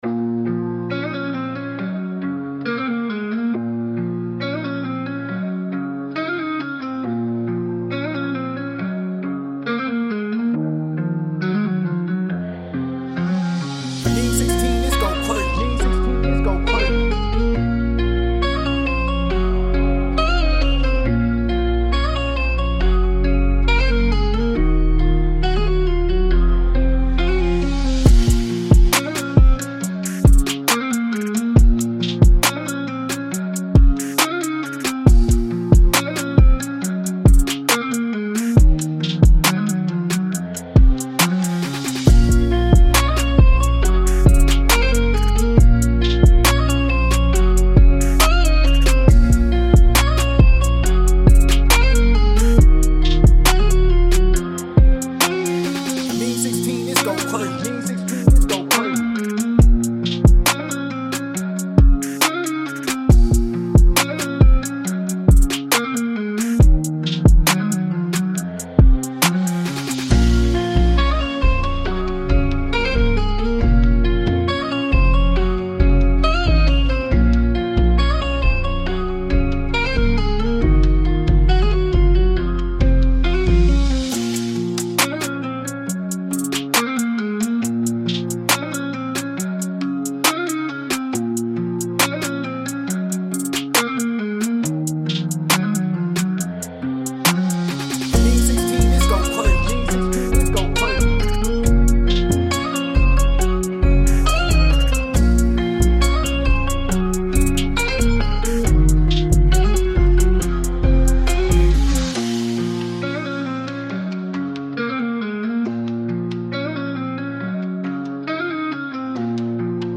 trap beats